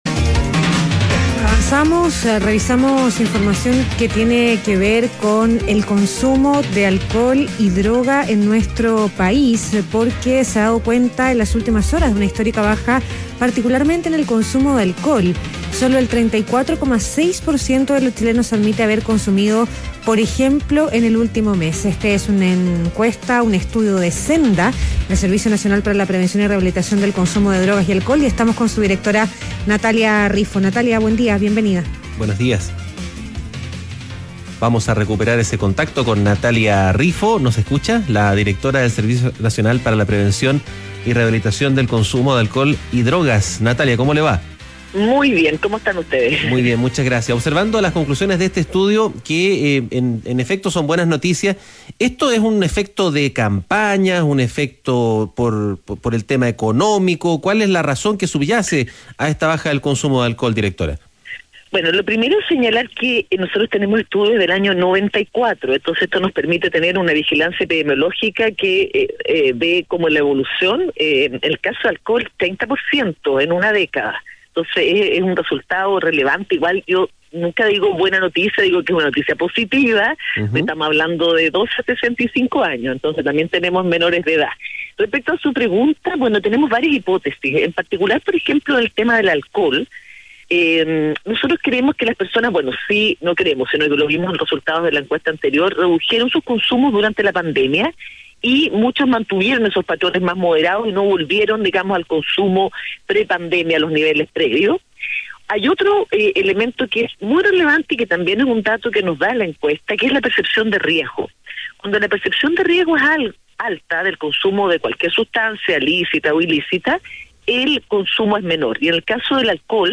ADN Hoy - Entrevista a Natalia Riffo, directora nacional de Senda